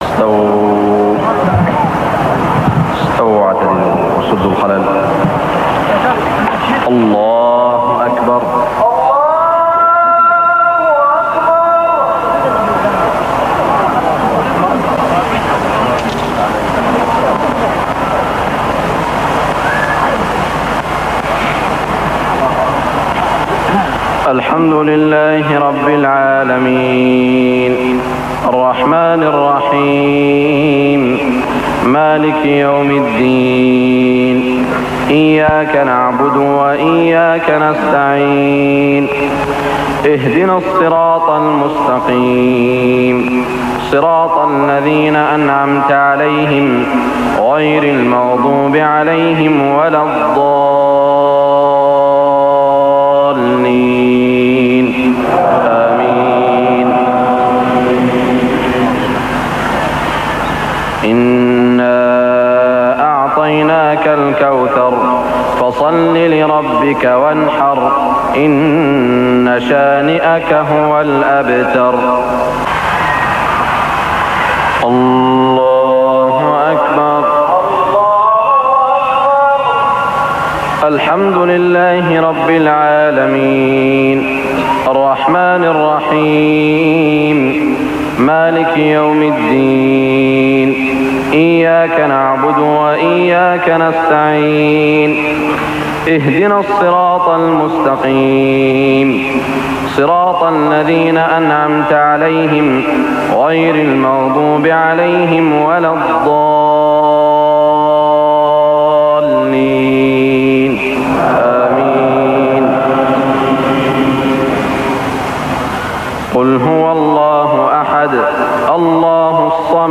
صلاة المغرب 9-9-1412هـ سورتي الكوثر و الإخلاص > 1412 🕋 > الفروض - تلاوات الحرمين